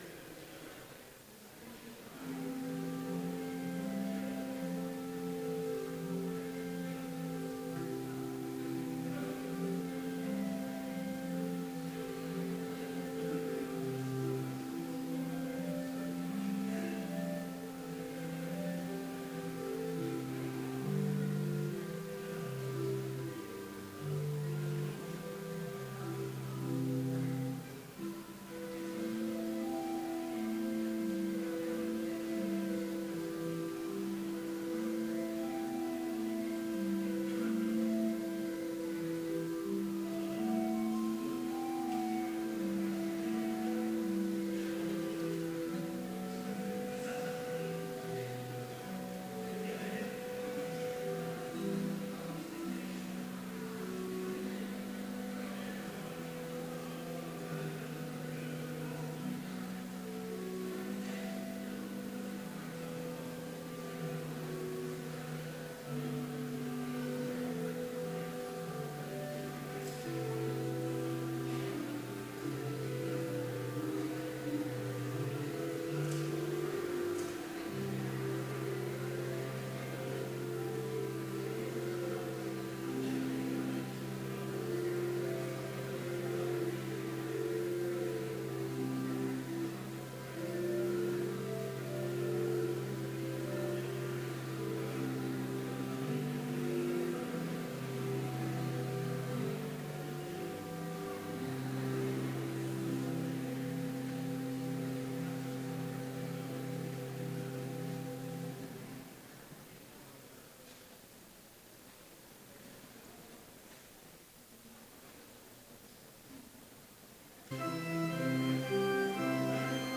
Complete service audio for Chapel - April 18, 2016
Hymn 376, vv. 1-5, Rejoice, the Lord is King
Hymn 589, Lord, Keep Us Steadfast in Thy Word